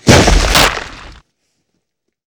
thump.wav